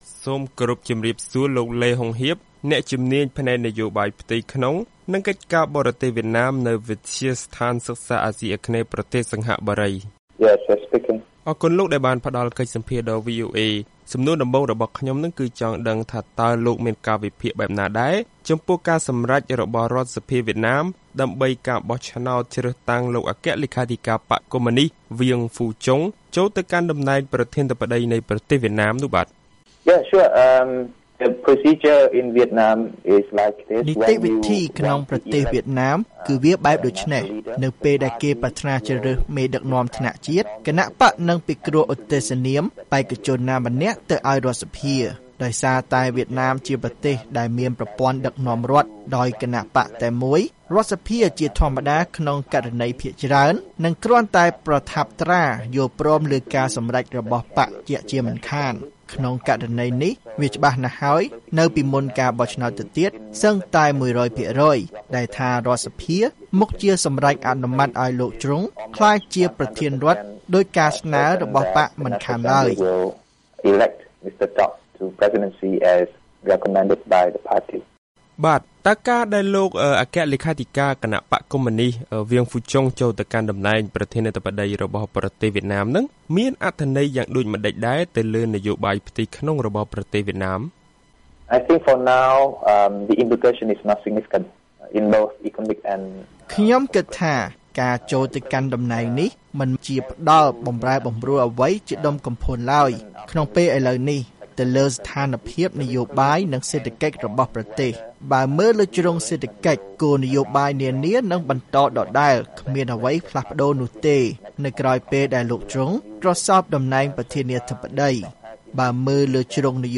បទសម្ភាសន៍ VOA៖ អ្នកវិភាគយល់ថាចរន្តនយោបាយវៀតណាមបន្ត ខណៈអគ្គលេខាធិការបក្សក្លាយជាប្រធានាធិបតី